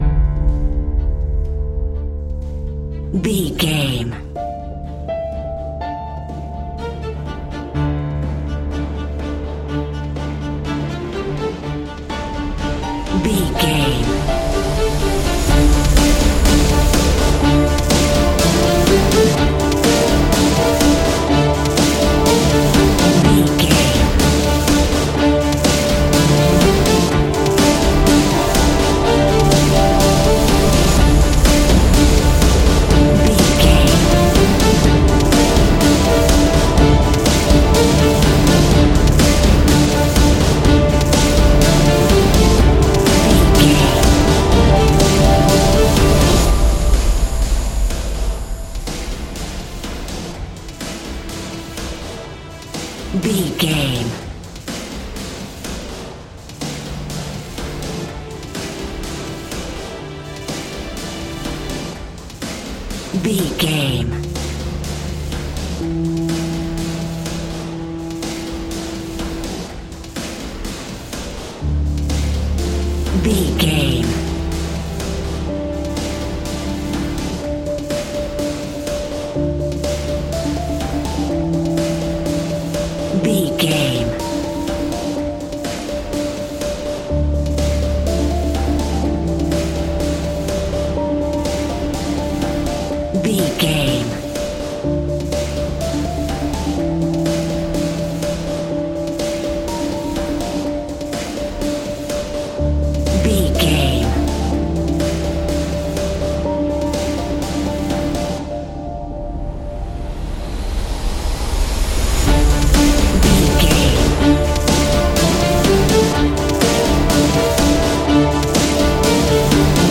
Ionian/Major
electric guitar
bass guitar